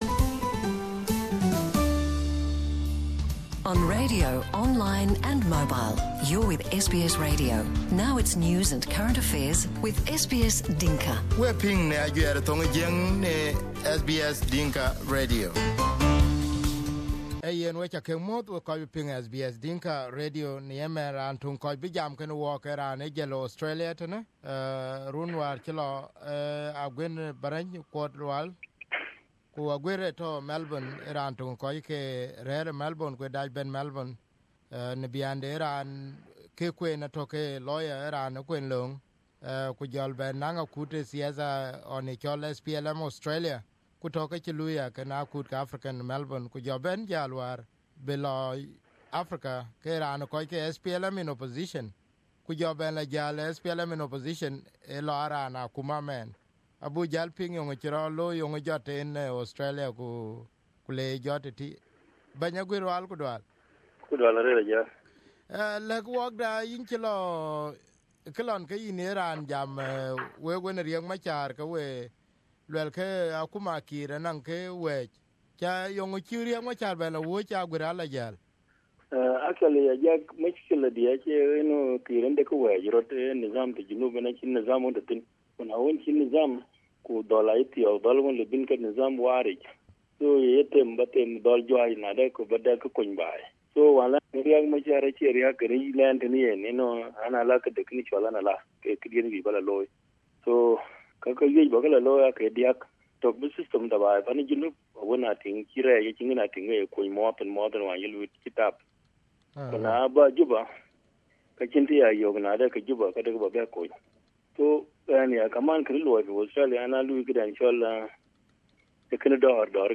Here is the exclusive interview on SBS Dinka Radio.